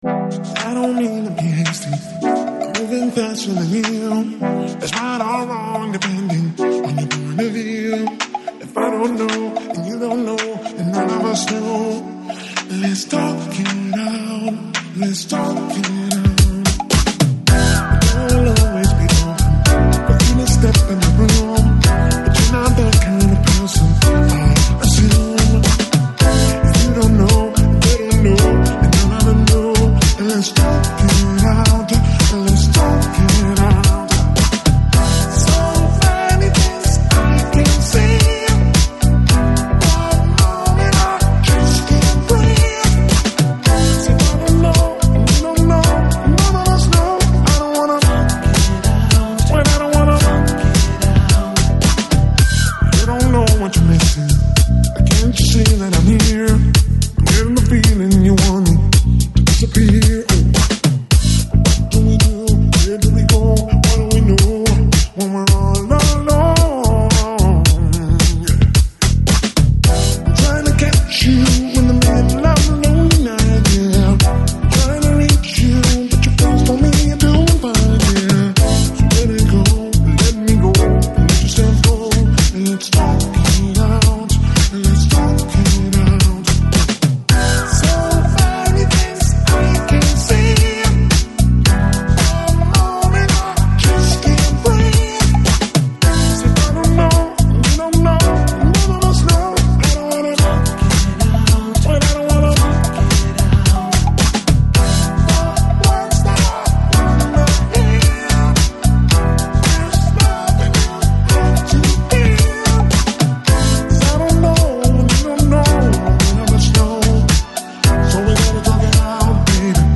Lounge, Chill House, Downtempo, Pop, Electronic Год издания